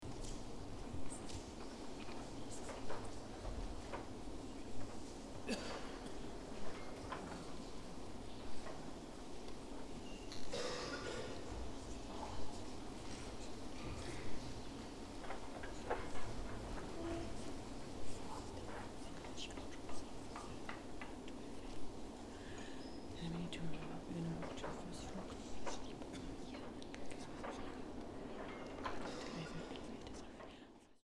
Vanligtvis fokuserar vi på det visuella och taktila, men här bad vi en ljuddesigner skapa några audiella exempel åt oss.
Först, en påtagligt "tyst" miljö.